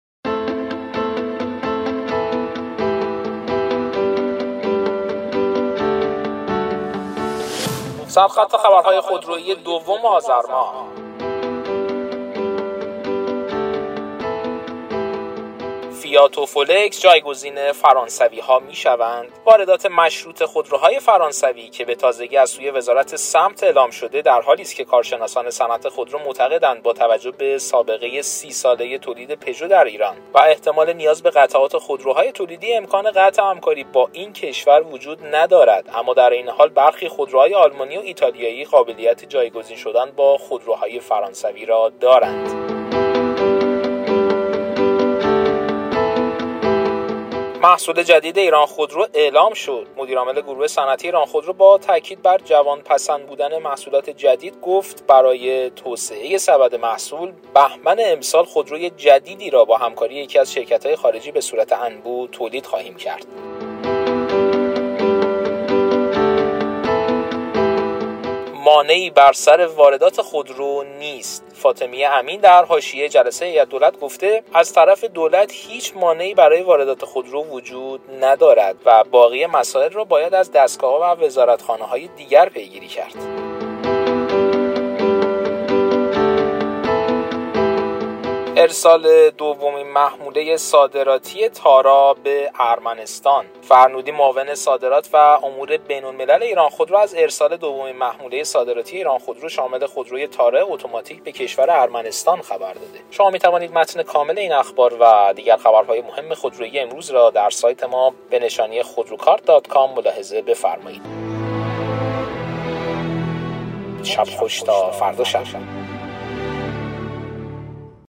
برچسب ها: خبر صوتی ، سر خط خبرها